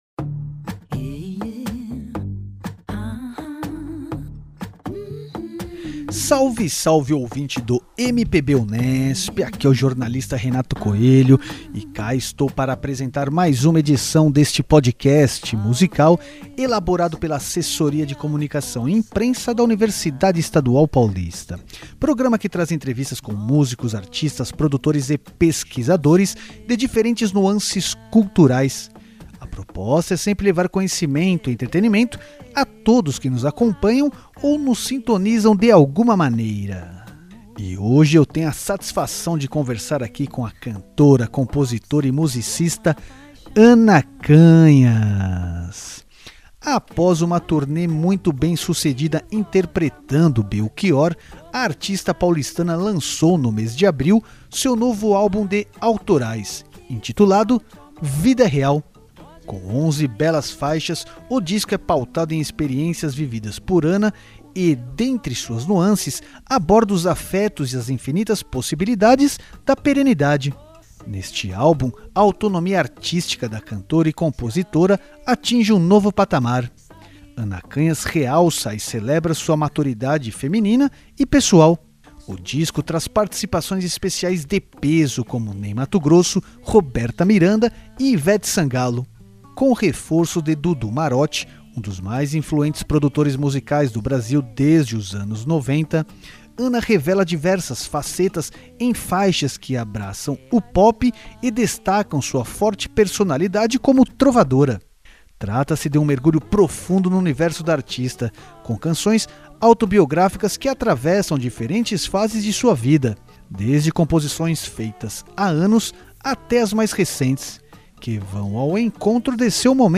Confira a entrevista completa nesta edição do MPB Unesp .